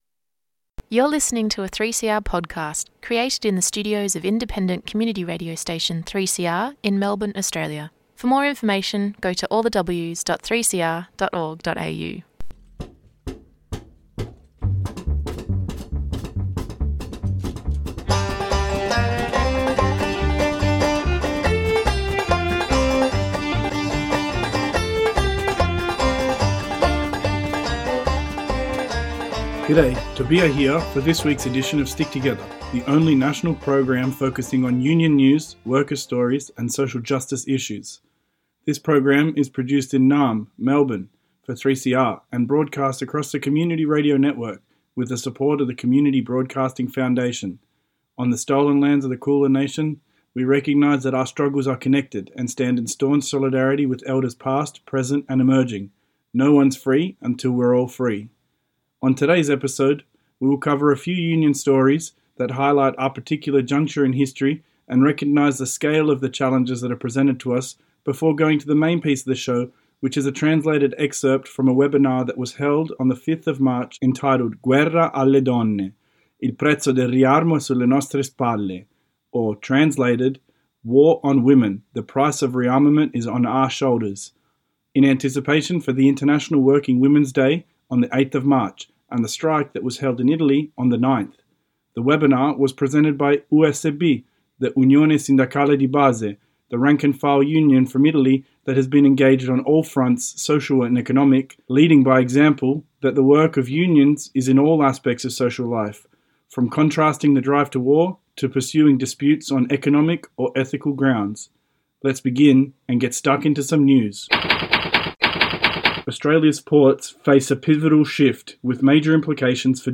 Tweet Stick Together Wednesday 8:30am to 9:00am Australia's only national radio show focusing on industrial, social and workplace issues.